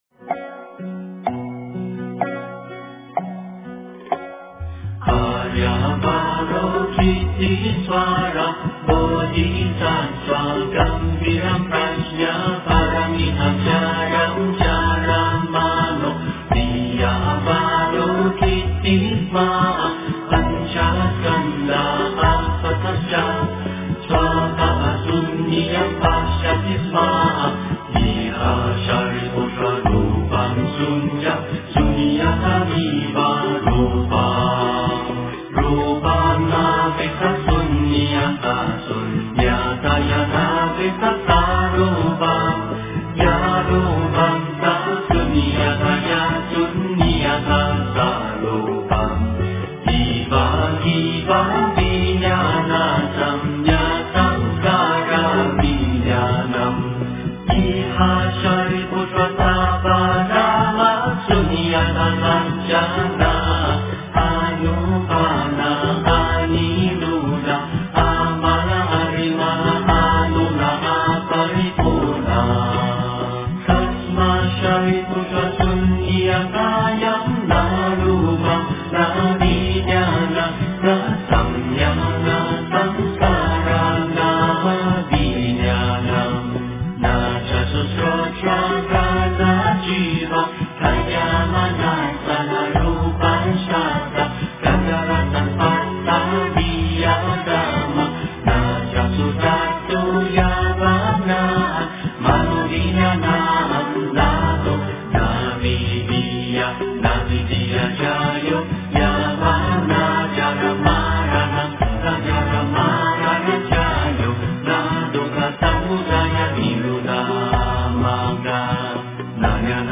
心经-梵音 诵经 心经-梵音--合唱版 点我： 标签: 佛音 诵经 佛教音乐 返回列表 上一篇： 六字大明咒 下一篇： 心经 相关文章 金光明经-除病品第十五 金光明经-除病品第十五--未知...